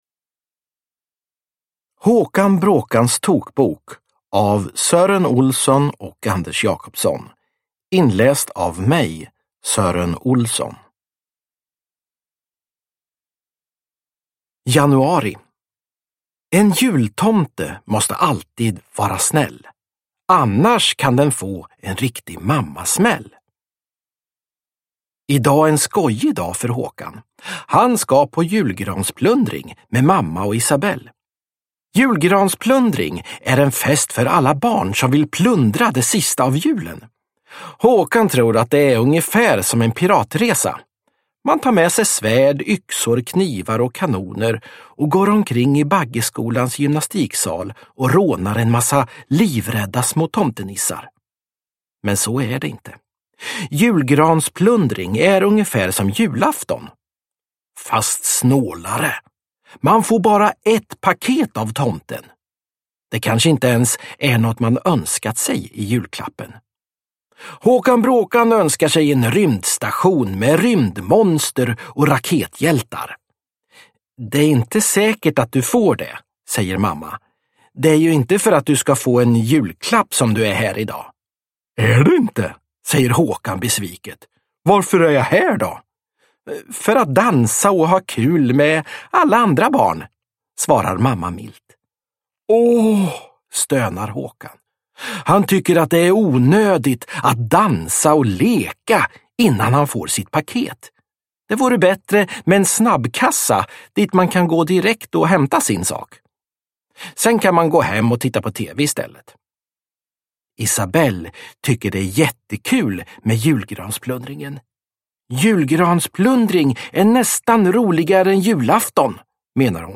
Håkan Bråkans tokbok – Ljudbok – Laddas ner
Uppläsare: Sören Olsson